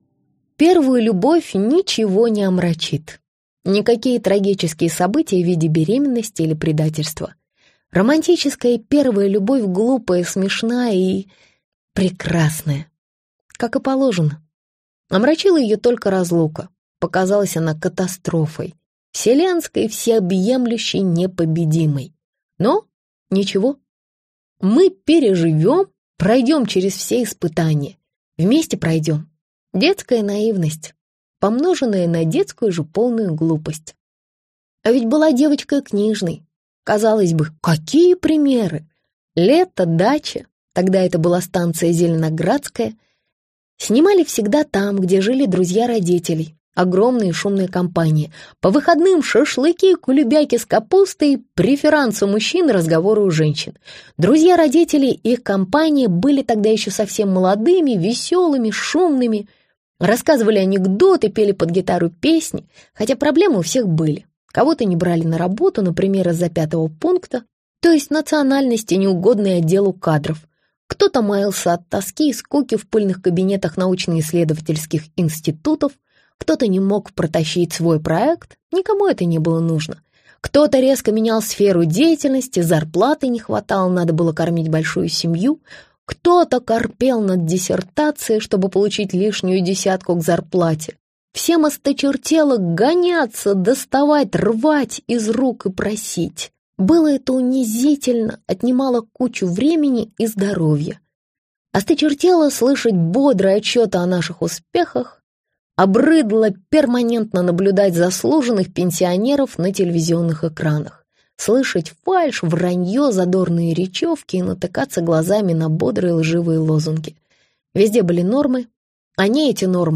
Аудиокнига Можно я побуду счастливой?